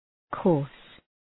{kɔ:rs}
coarse.mp3